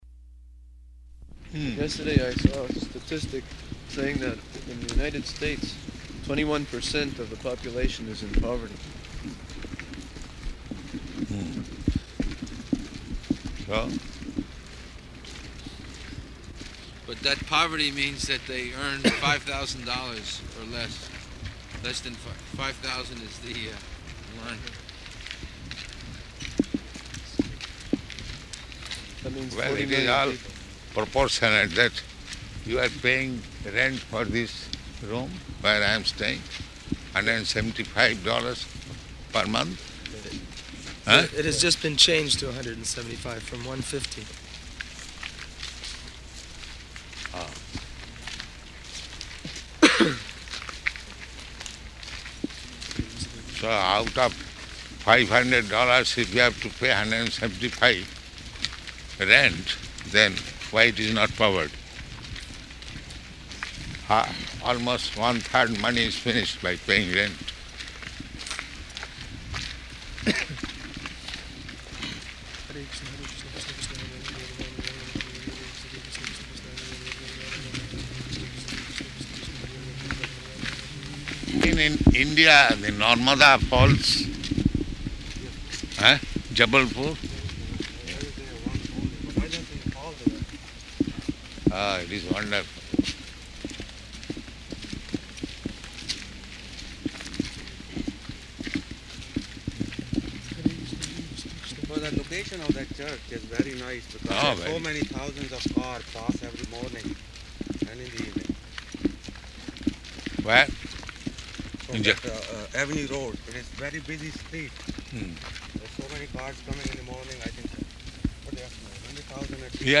Morning Walk --:-- --:-- Type: Walk Dated: August 7th 1975 Location: Toronto Audio file: 750807MW.TOR.mp3 Prabhupāda: Hmm.